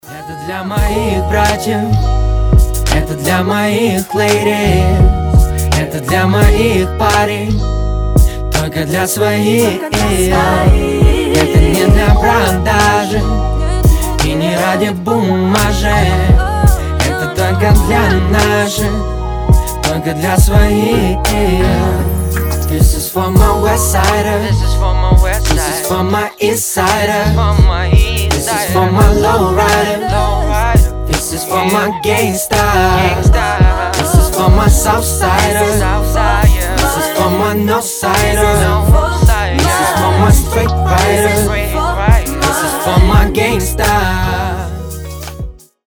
• Качество: 320, Stereo
позитивные
RnB
добрые